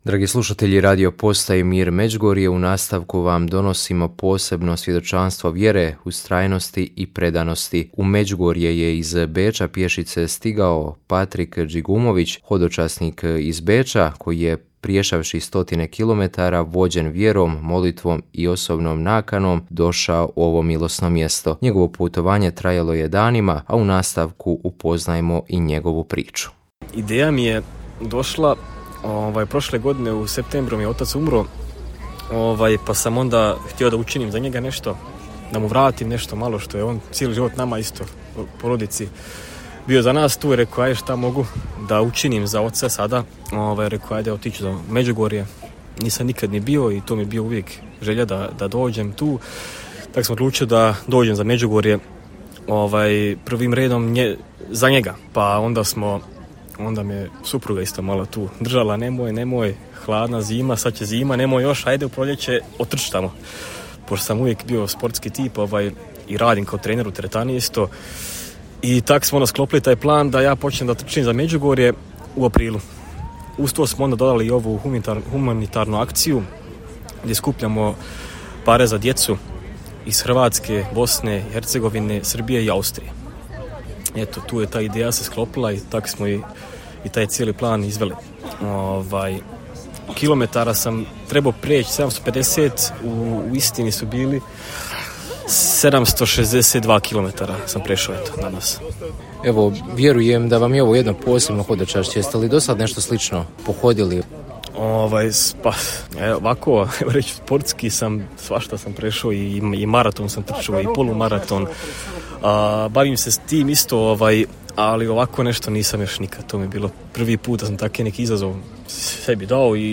Vijesti
Bili su to razlozi da ga dočekamo ispred međugorske crkve sv. Jakova kako bi i s našim slušateljima podijelio svoje dojmove o ovom hodočašću.